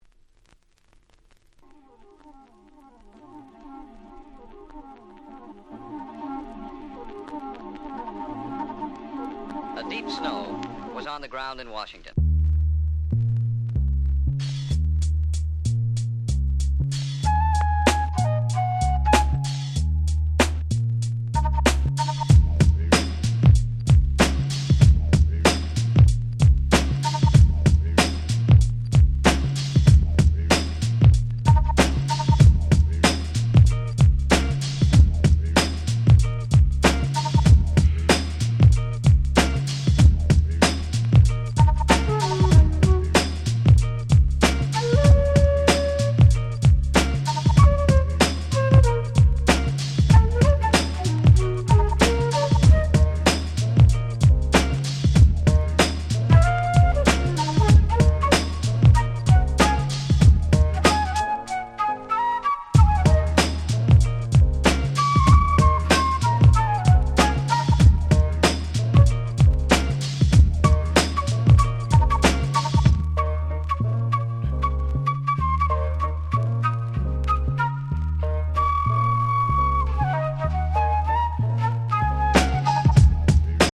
93’ Nice Hip Hop !!